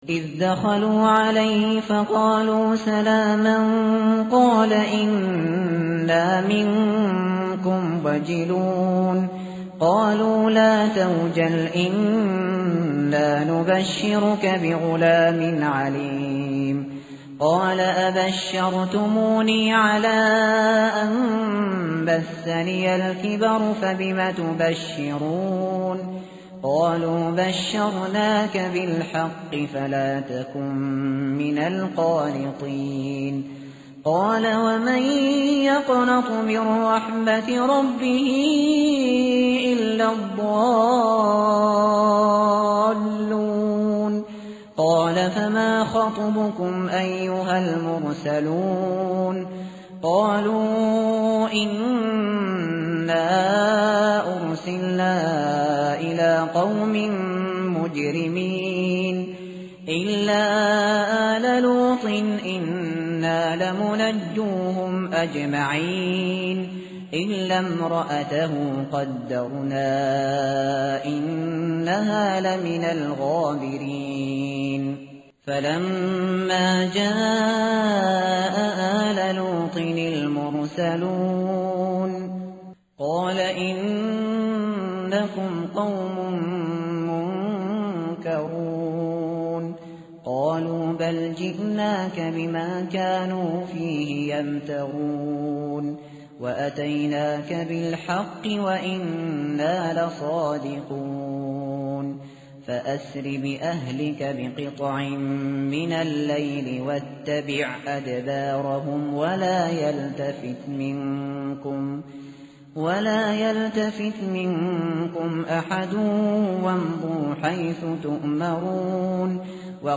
متن قرآن همراه باتلاوت قرآن و ترجمه
tartil_shateri_page_265.mp3